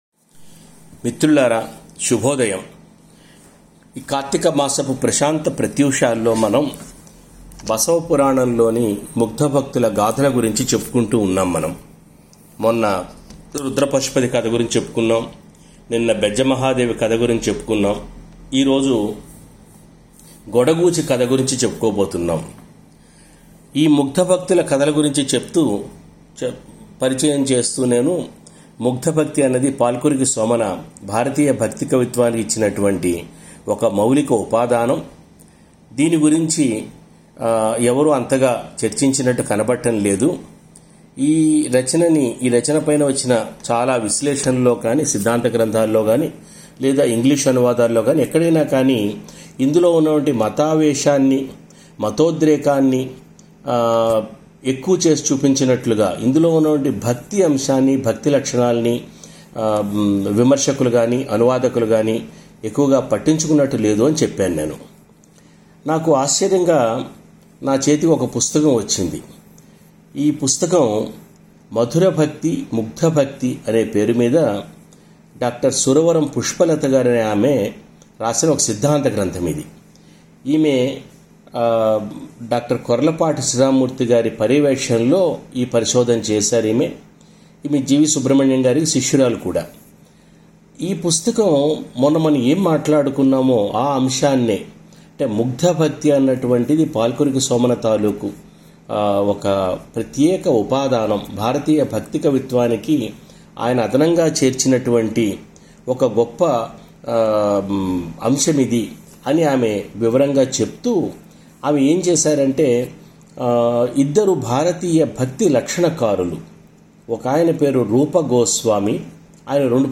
బసవపురాణంలో ముగ్ధ భక్తుల గురించిన ప్రసంగాల్లో మూడవ రోజు ప్రసంగం గొడగూచి కథ గురించి.